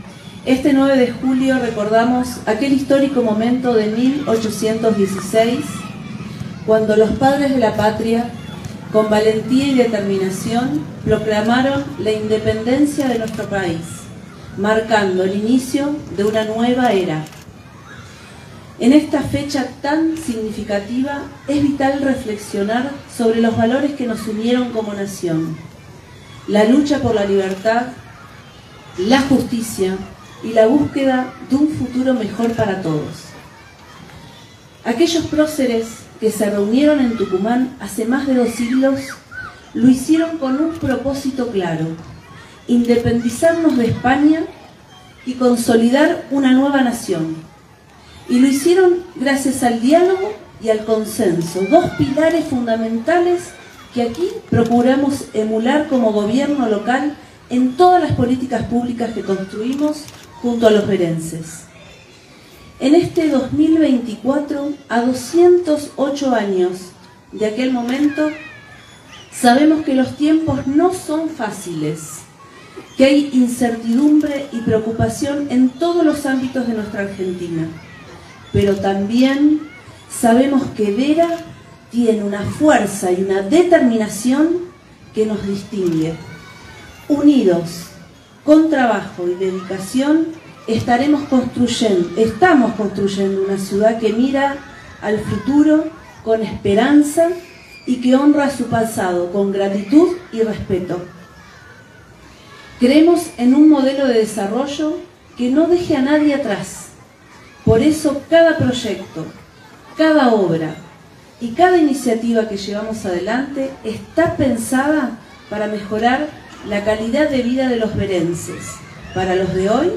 En un acto presidido por la Vicegobernadora de la Provincia de Santa Fe, Lic. Gisela Scaglia y la Intendente Municipal, Ing. Paula Mitre, acompañadas por el Senador, Osvaldo Sosa, Concejales y demás autoridades, la Municipalidad de Vera realizó el acto oficial en conmemoración al 9 de Julio de 1816.
Intendente Municipal – Paula Mitre